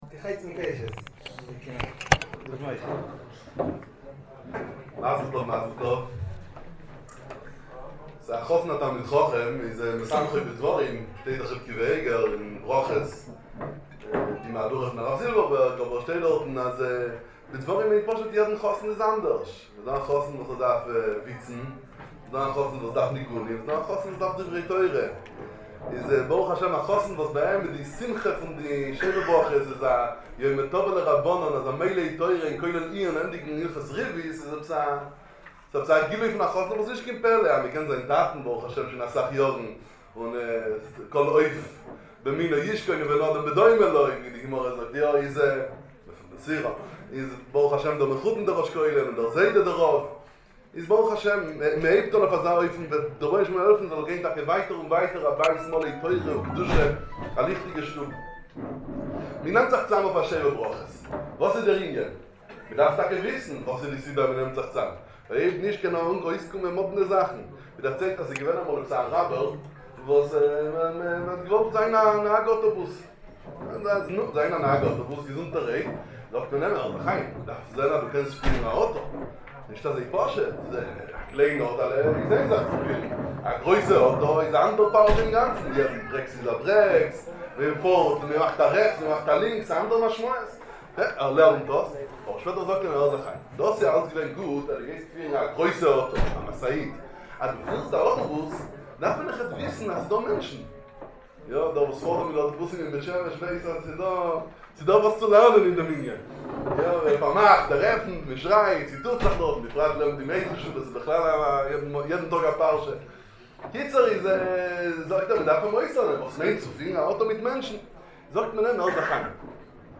דרשת חיזוק לאברכי הכולל